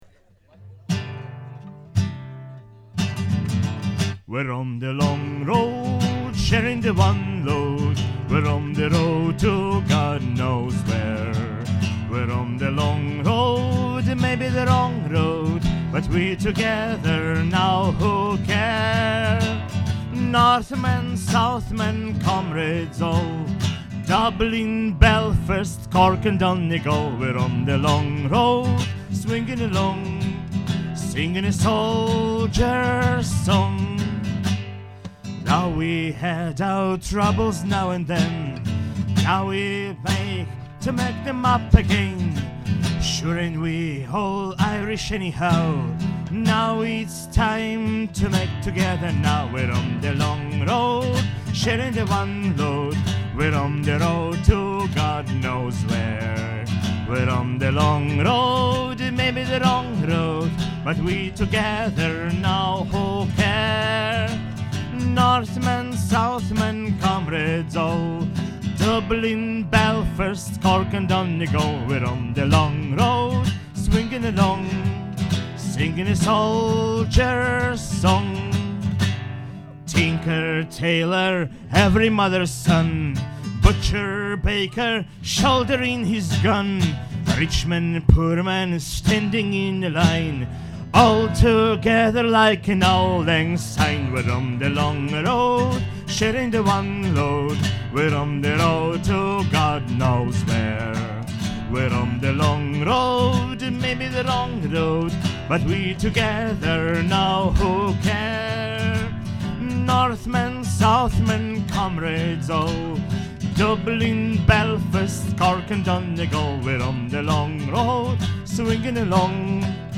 Запись концерта БС2002
"On The Long Road" (Ирландская народная).